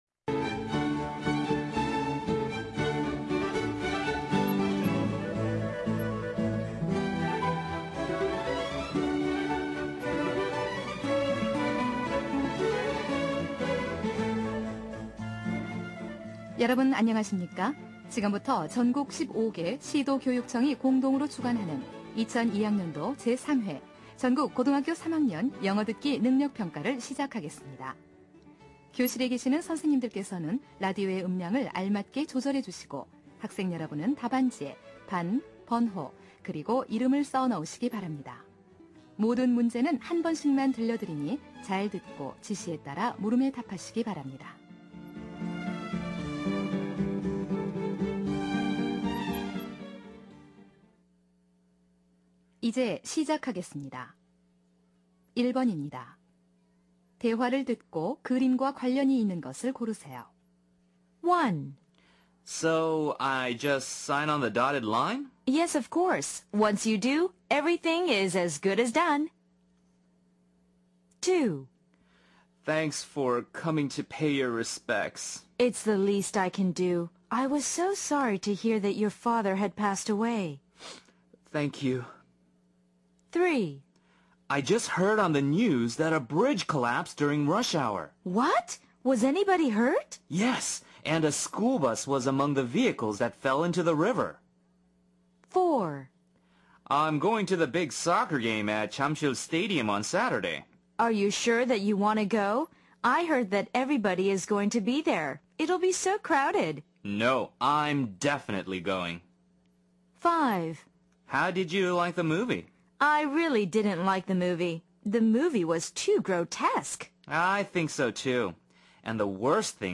2002학년도 3회 3학년 듣기평가